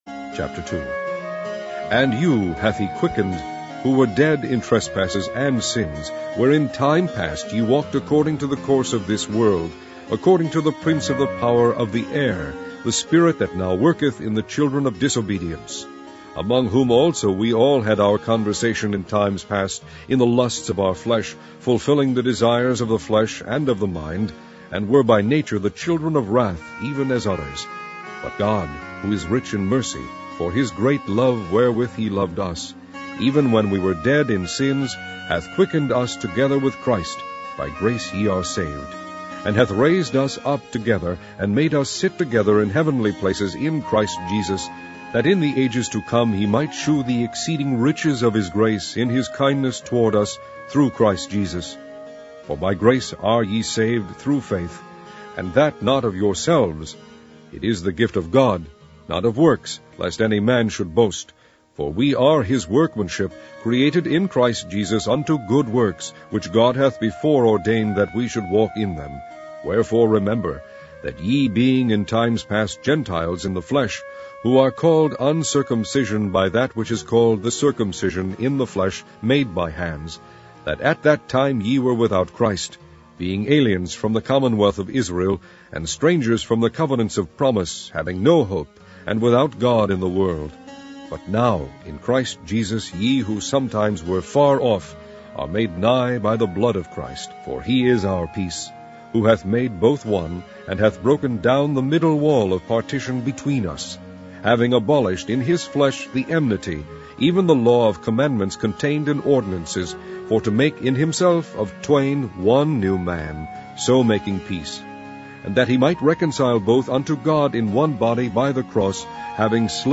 Online Audio Bible - King James Version - Ephesians